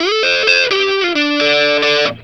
BLUESY3 D 90.wav